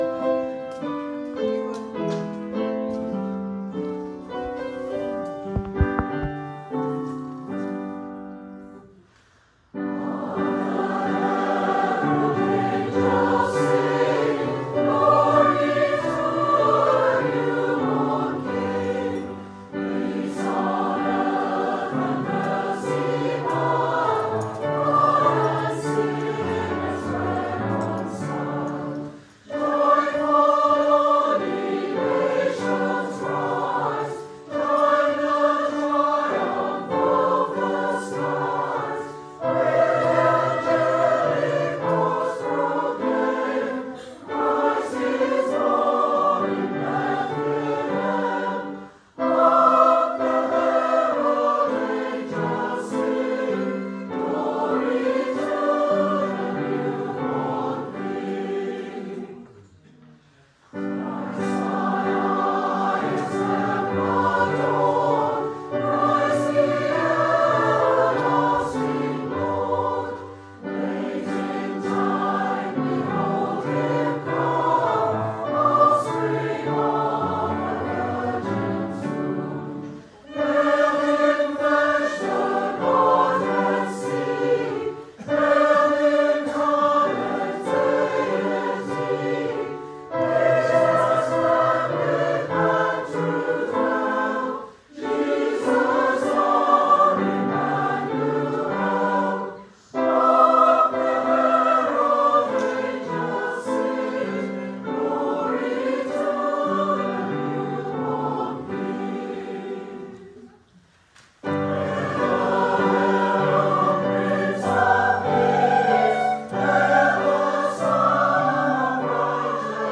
Congregational carols - recordings sung by the choir: